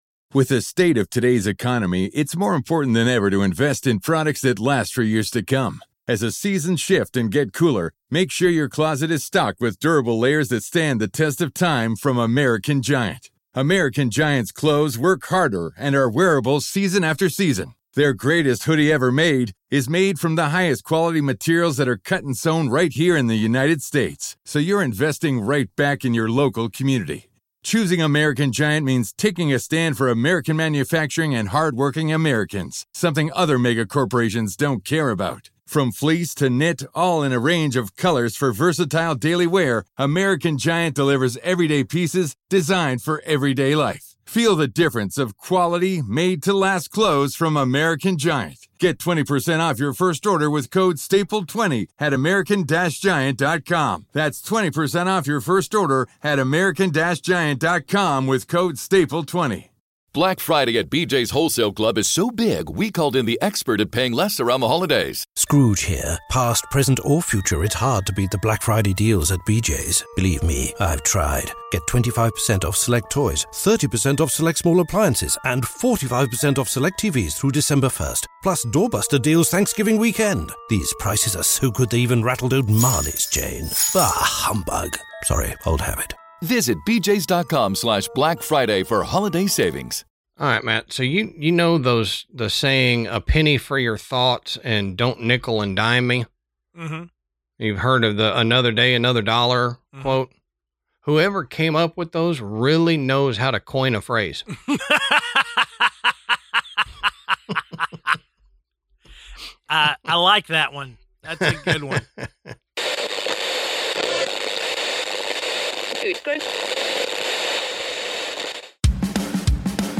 It was a great conversation and The Void is a great podcast!